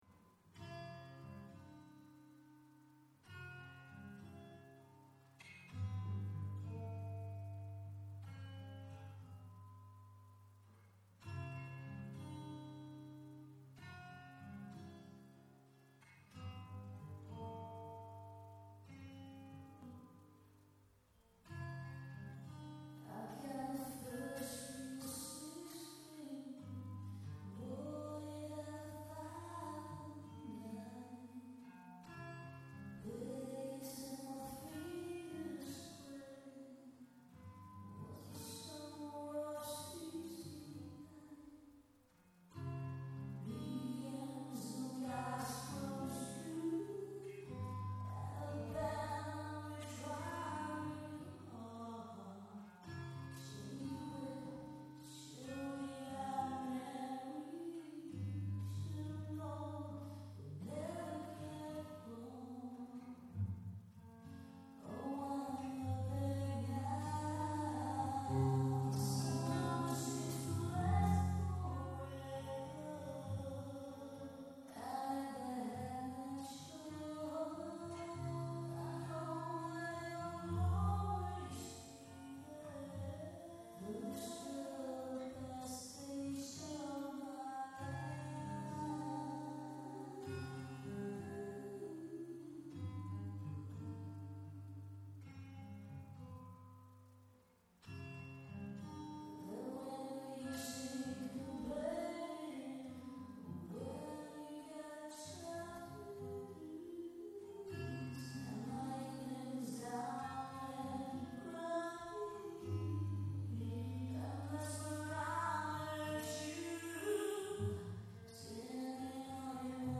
hat aber nichts mit dem Original gemein